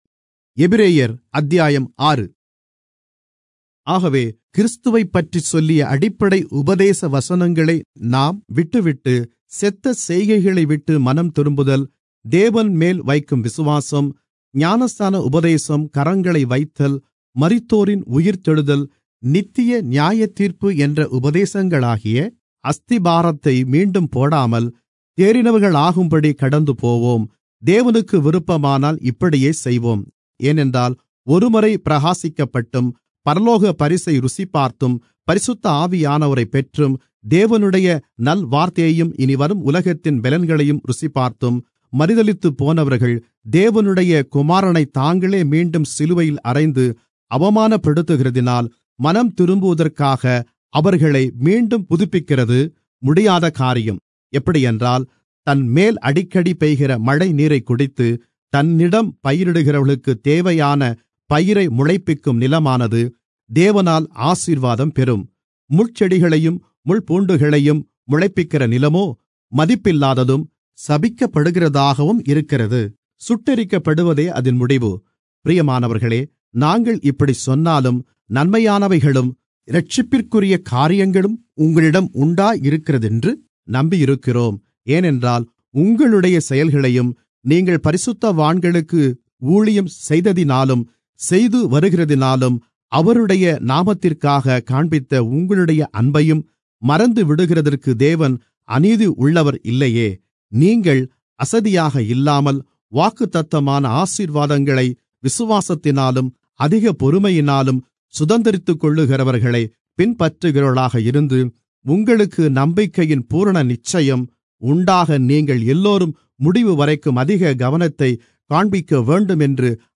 Tamil Audio Bible - Hebrews 9 in Irvta bible version